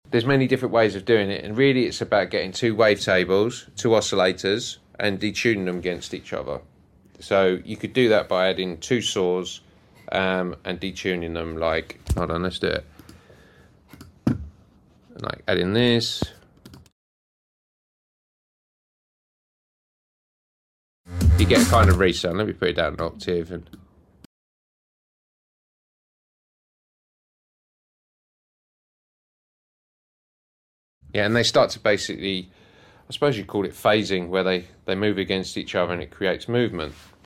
The Reese Bass is one of the most iconic drum and bass sounds.